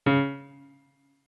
MIDI-Synthesizer/Project/Piano/29.ogg at 51c16a17ac42a0203ee77c8c68e83996ce3f6132